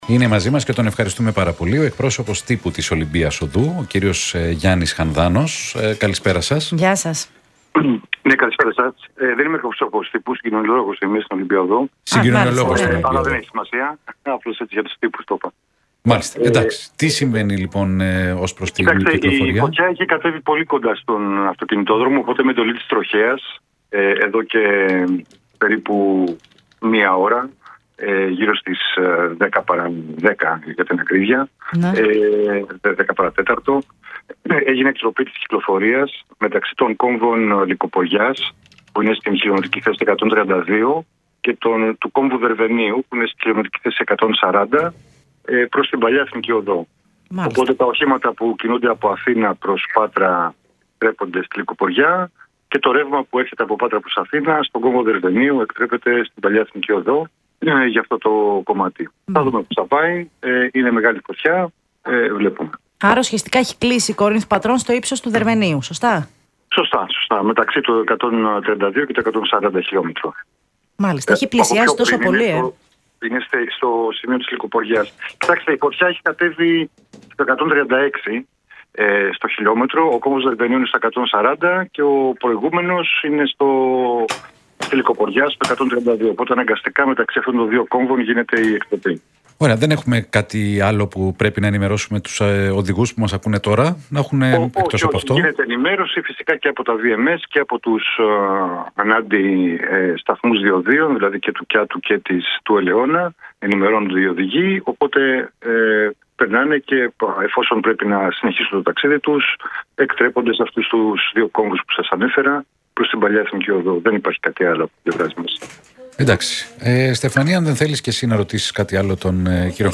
Μιλώντας στον Real FM 97.8